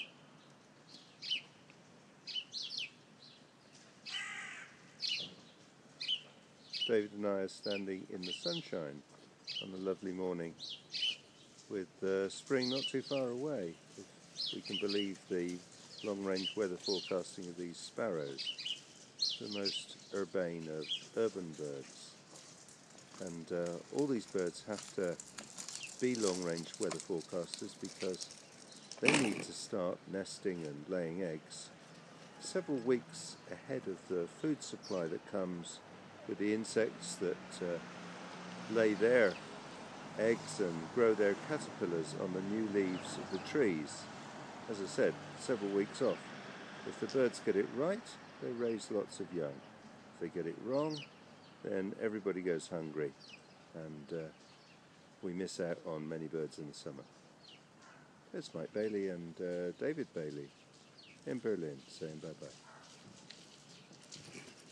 Chirping sparrows are pairing off in the pre-spring sunshine. they are predicting spring's arrival several weeks ahead.